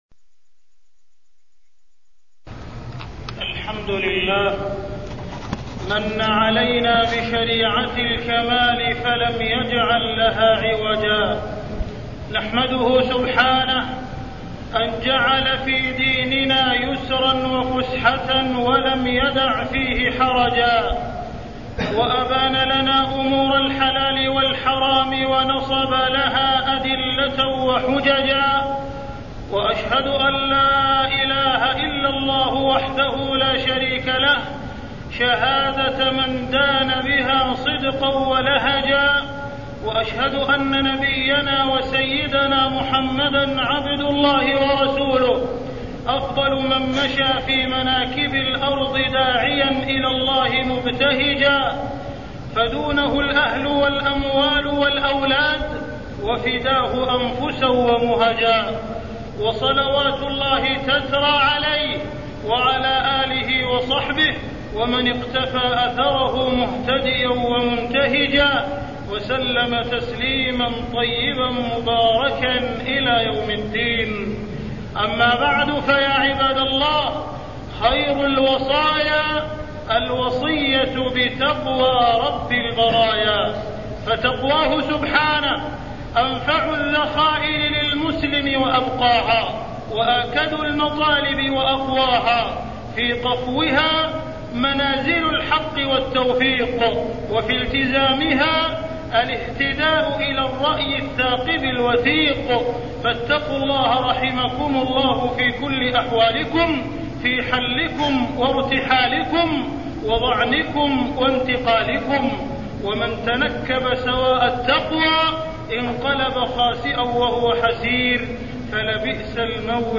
تاريخ النشر ٢٥ جمادى الأولى ١٤٢٤ هـ المكان: المسجد الحرام الشيخ: معالي الشيخ أ.د. عبدالرحمن بن عبدالعزيز السديس معالي الشيخ أ.د. عبدالرحمن بن عبدالعزيز السديس السفر والسياحة